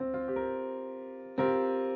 Play, download and share c7sus4 original sound button!!!!
c7sus4.mp3